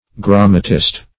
Search Result for " grammatist" : The Collaborative International Dictionary of English v.0.48: Grammatist \Gram"ma*tist\, n. [L. grammatista schoolmaster, Gr.